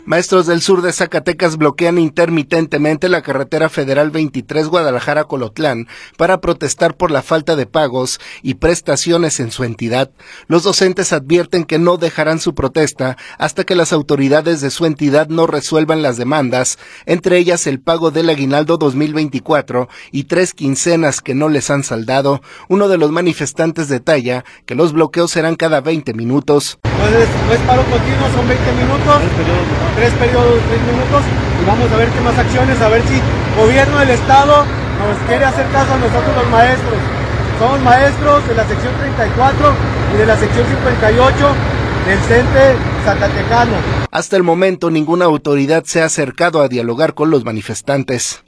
Los docentes advierten que no dejarán su protesta hasta las autoridades de su entidad no resuelvan las demandas, entre ellas el pago del aguinaldo 2024 y de tres quincenas que no les han saldado. Uno de los manifestantes detalla que los bloqueos serán cada 20 minutos.